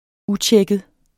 Udtale [ ˈuˌtjεgəð ]